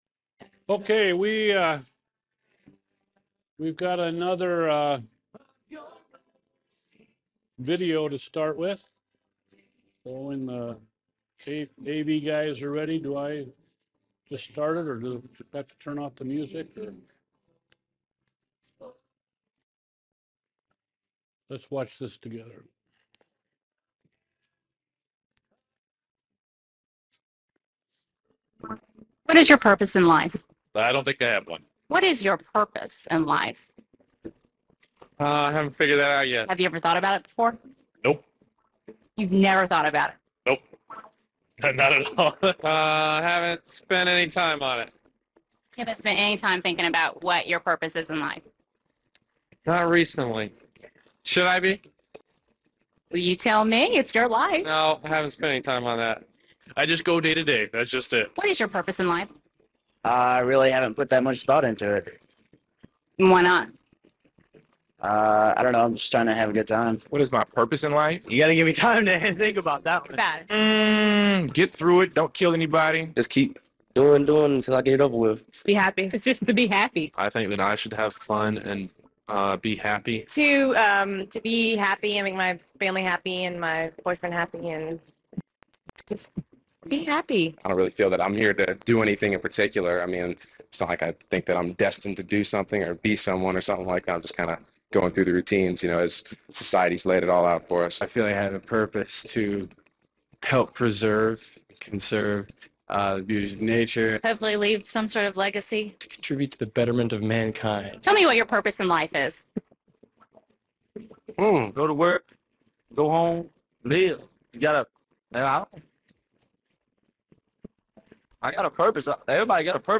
Seminar recordings from Evangelism Shift.